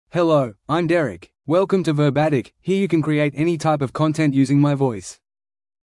MaleEnglish (Australia)
DerekMale English AI voice
Derek is a male AI voice for English (Australia).
Voice sample
Listen to Derek's male English voice.
Derek delivers clear pronunciation with authentic Australia English intonation, making your content sound professionally produced.